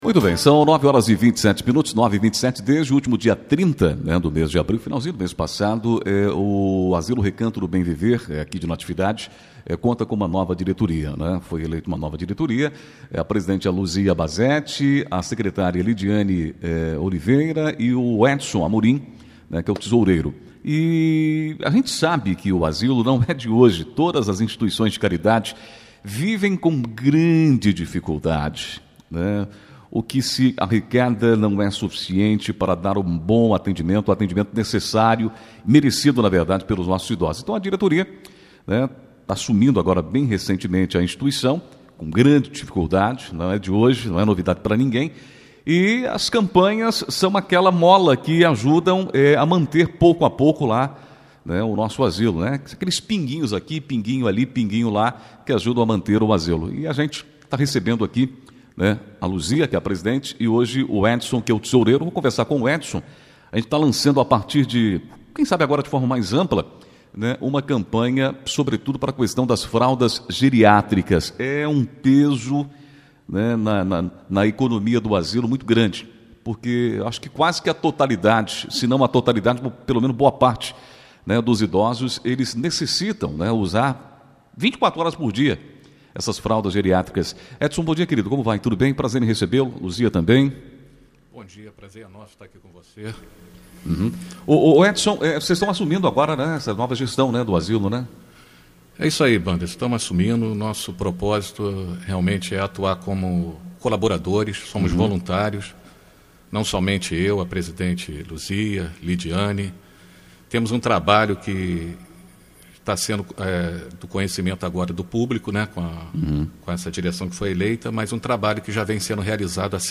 Parte da nova diretoria, que no final do último mês assumiu o Asilo Recanto do Bem Viver, visitou a Rádio Natividade na manhã desta segunda-feira (10), onde fez rápido balanço da grave situação financeira em que se encontra a entidade, que atualmente abriga 36 idosos, com hospedagem, alimentação, assistência médica e medicamentos, 24 horas por dia.
10 maio, 2021 ENTREVISTAS, NATIVIDADE AGORA